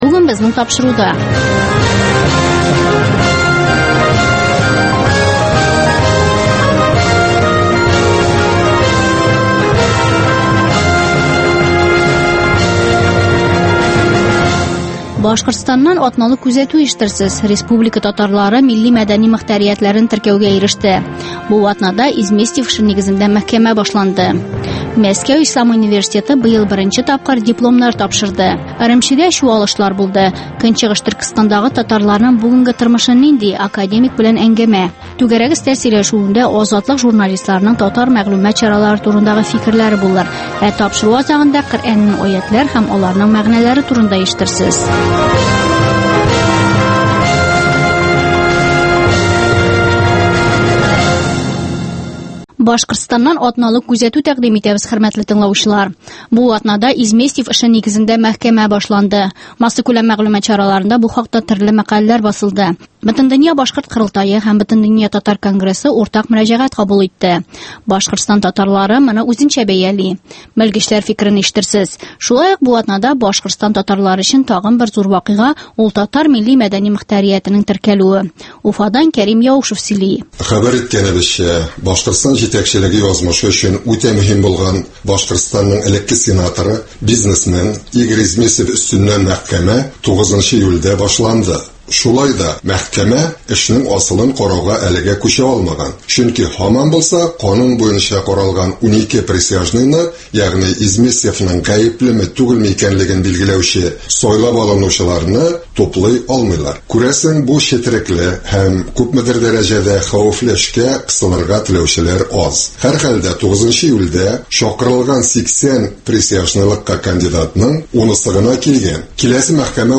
Азатлык узган атнага күз сала - башкортстаннан атналык күзәтү - татар дөньясы - түгәрәк өстәл артында сөйләшү